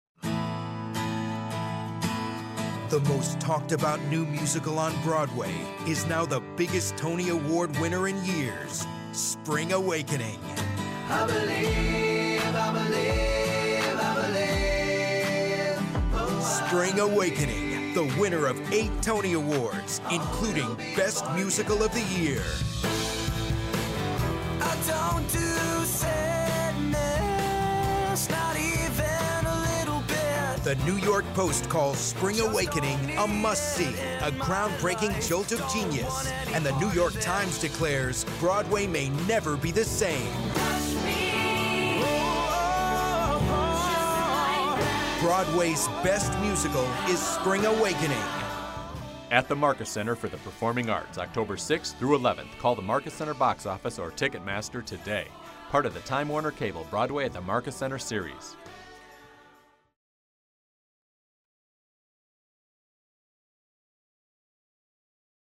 Spring Awakening Radio Commercial